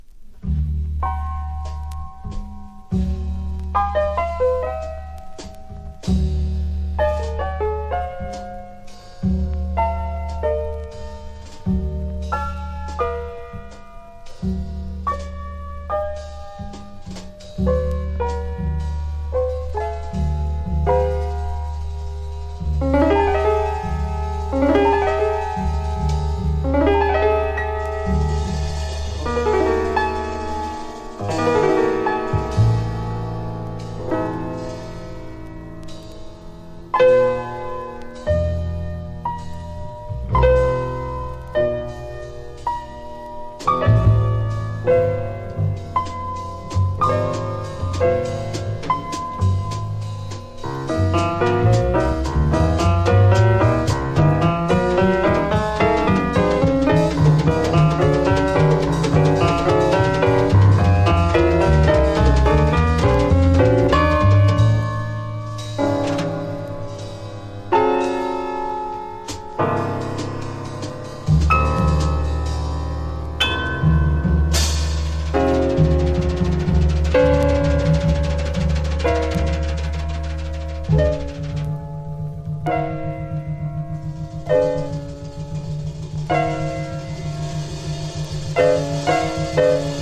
スリリングなピアノ・スウィングから美しいスロウ・ナンバーまでとにかく素晴らしいの一言！